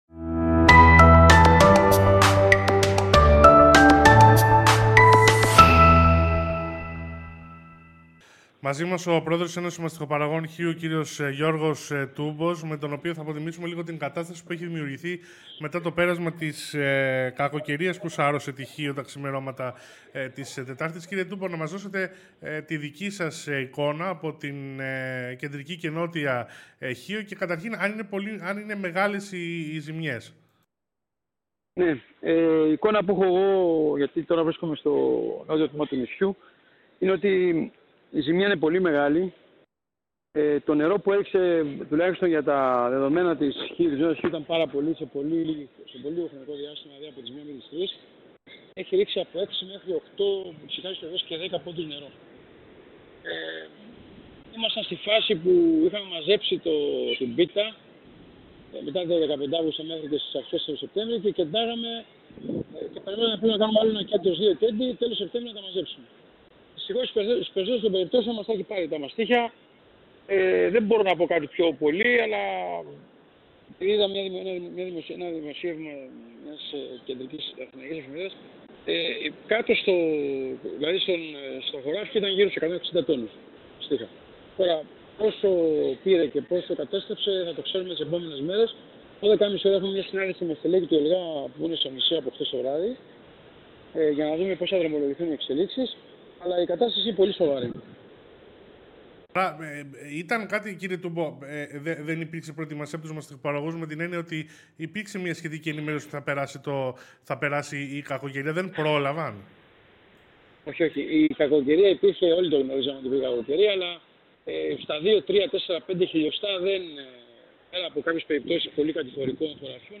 Δηλώσει